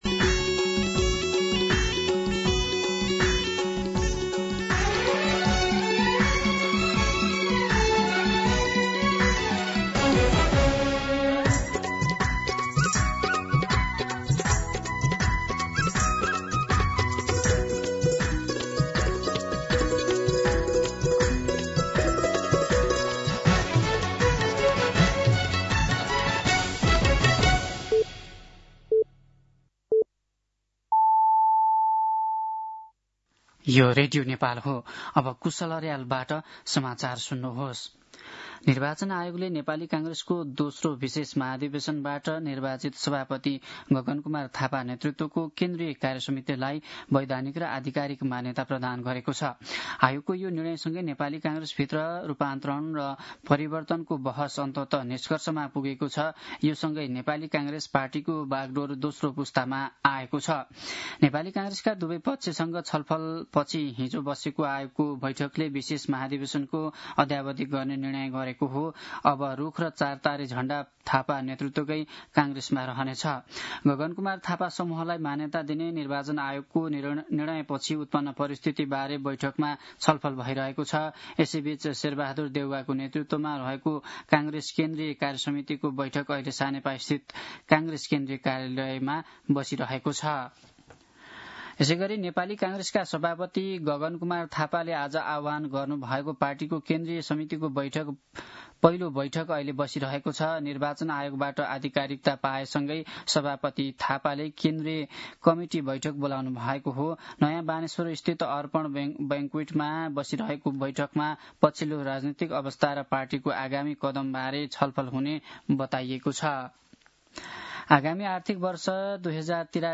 मध्यान्ह १२ बजेको नेपाली समाचार : ३ माघ , २०८२
12pm-Nepali-News.mp3